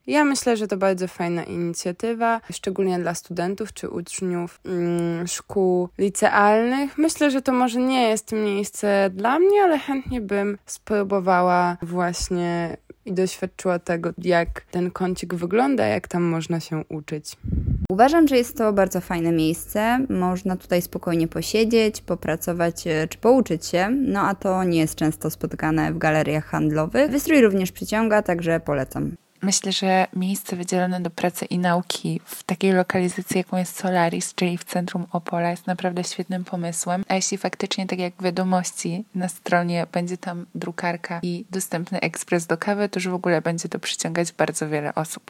O wrażenia na temat nowej strefy, zapytaliśmy studentów Uniwersytetu Opolskiego: